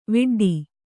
♪ viḍḍi